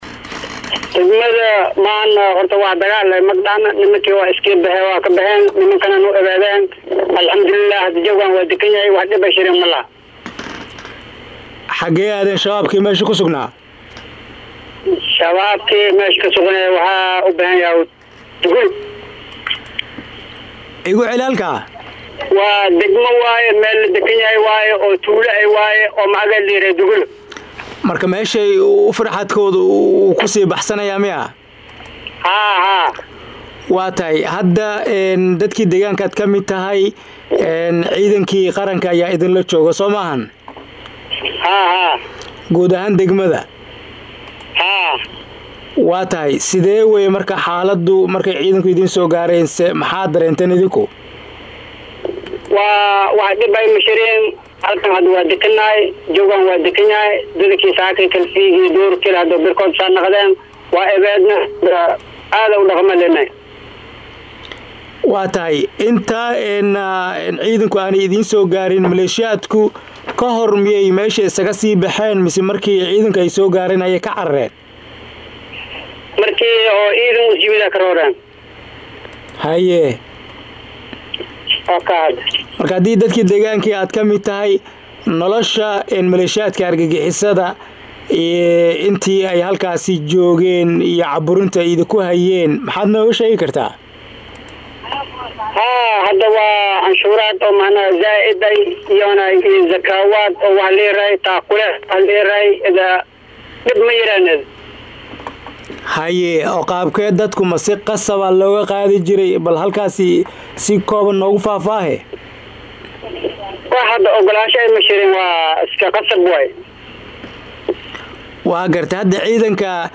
Mid ka mid ah dadka degaanka oo la hadlay radio Muqdisho ayaa tilmaamay inay aad u soo dhaweynayaan howlgalka ay ciidamada xoogga kula wareegeen deegaanka, halkaasi oo ay ciidamada ka qeybiyeen buskut iyo waxyaabo kale.
WARYSI-GOOBJOGE-KU-SUGAN-TOROTOROOW.mp3